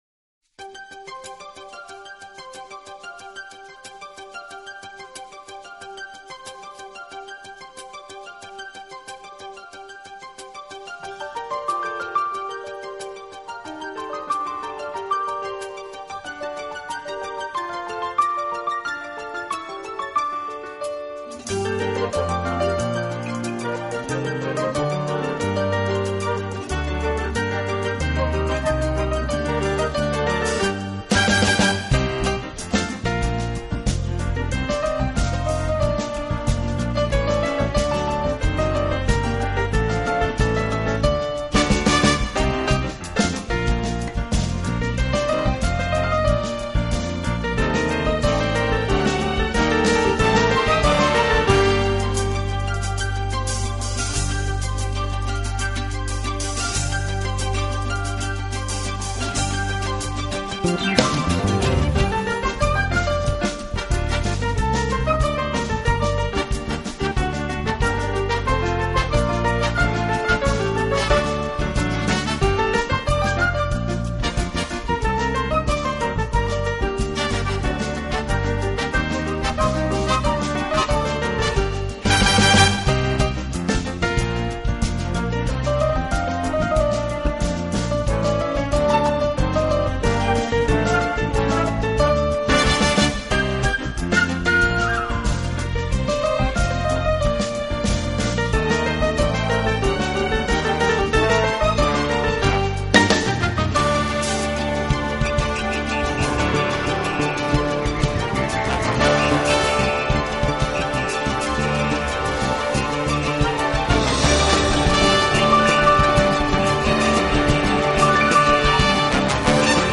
Genre: Latin Piano